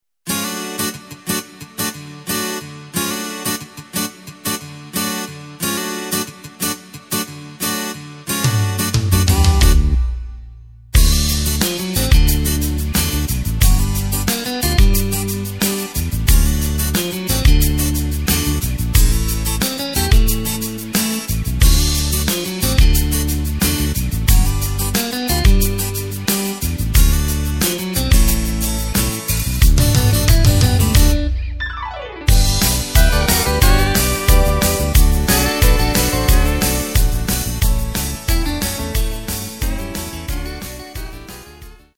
Takt: 4/4 Tempo: 90.00 Tonart: D
Country Song